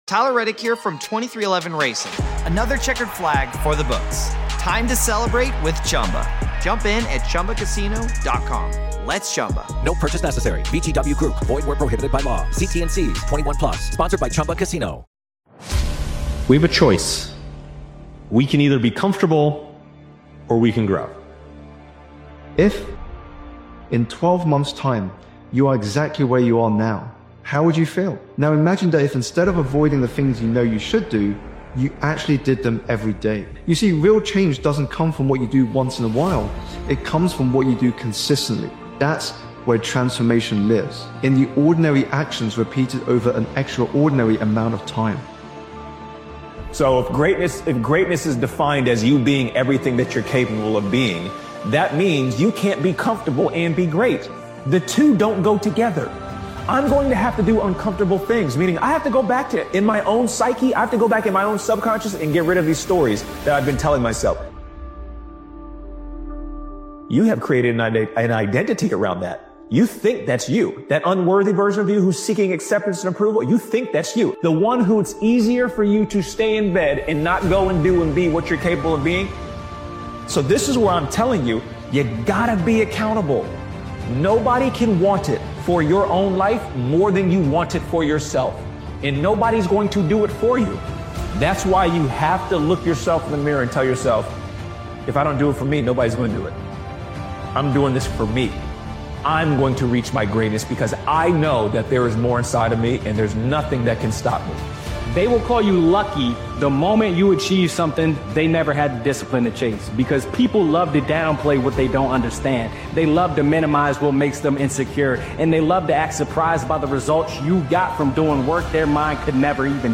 Powerful Motivational Speech is a hopeful and resilience-driven motivational speech created and edited by Daily Motivations. This powerful motivational speeches compilation is a reminder that your timeline isn’t fixed and your past doesn’t lock you in. No matter how far off track you feel, you can rebuild your habits, your mindset, and your direction.